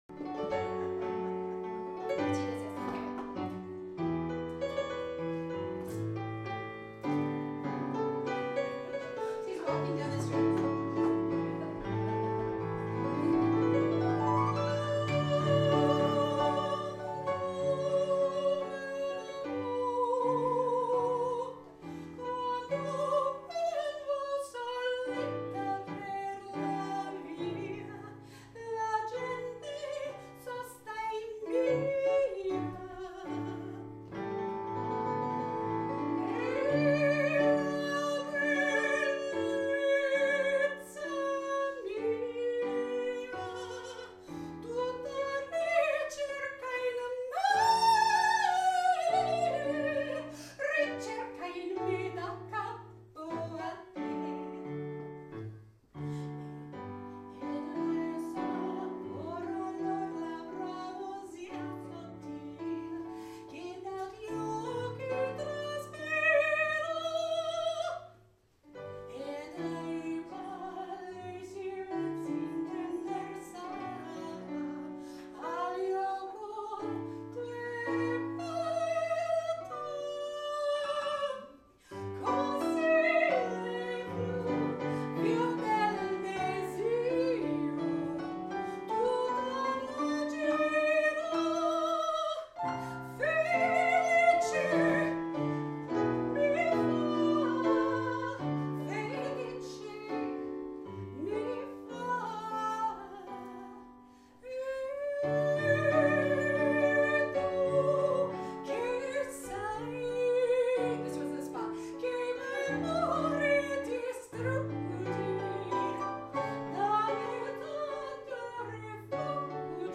singing At Last at Dazzle Jazz Club                                        Classical Italian Arias & Art Songs: